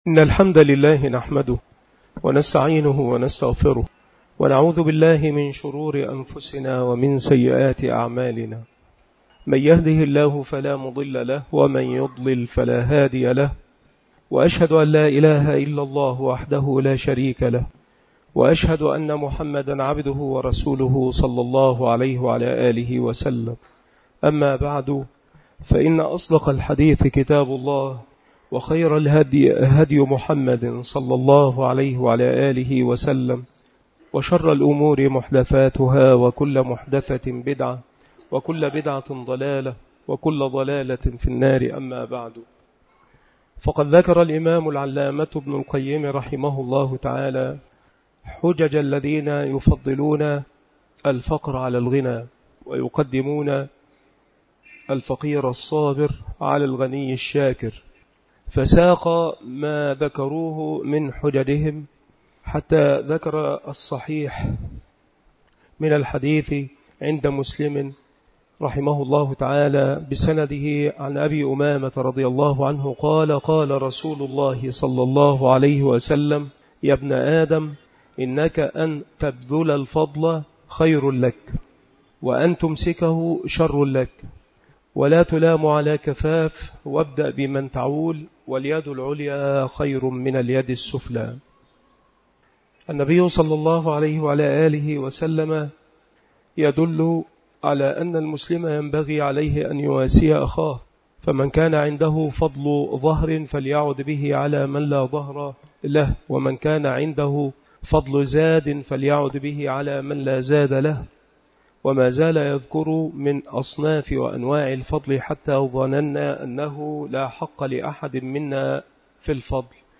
مكان إلقاء هذه المحاضرة بمسجد أولاد غانم بمدينة منوف - محافظة المنوفية - مصر